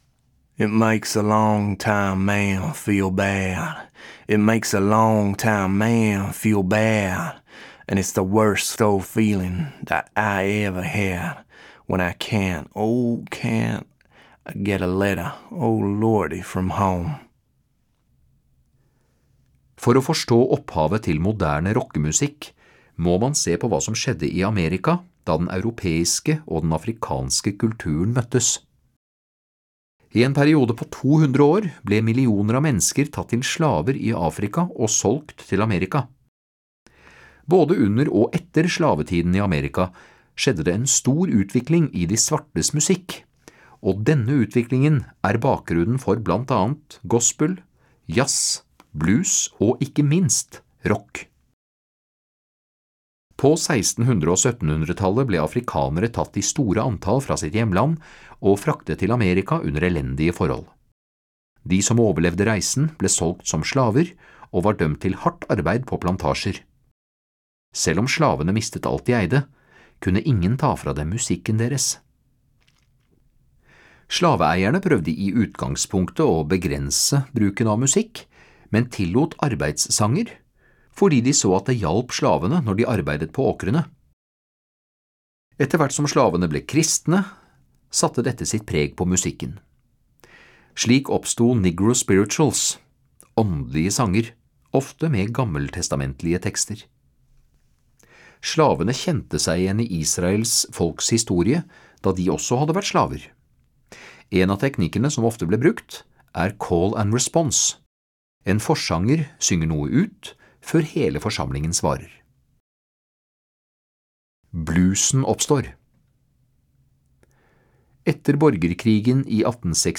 («It Makes a Long Time Man Feel Bad» – tradisjonell)